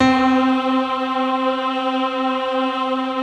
SI1 PIANO07R.wav